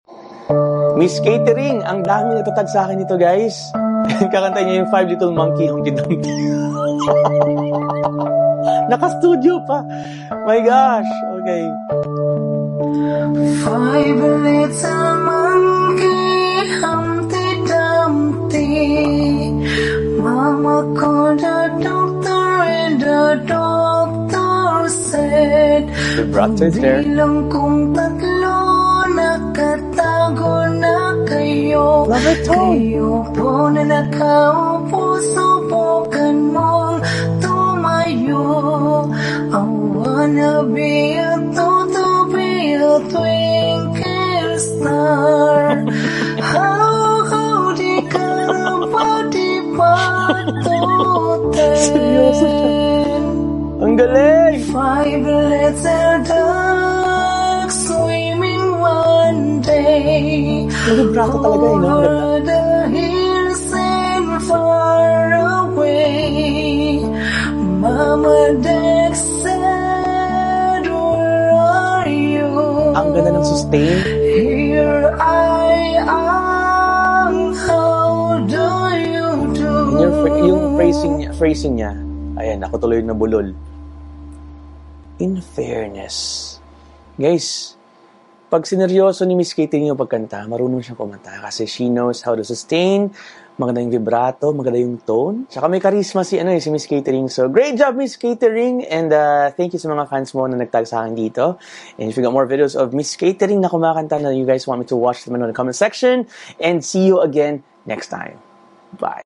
Marunong siya kumanta, she has a melodious voice, pleasant tone and a natural vibrato.